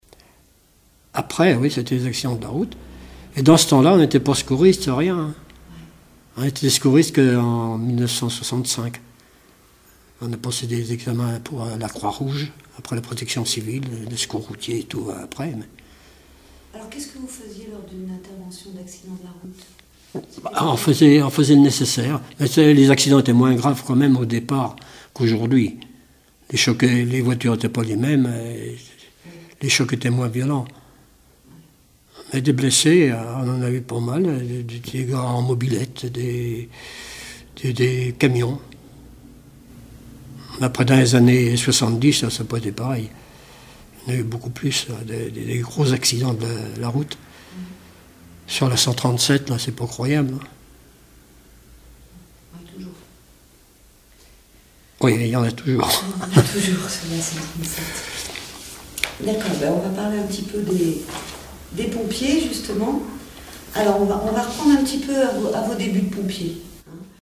Témoignages d'un ancien sapeur-pompier
Catégorie Témoignage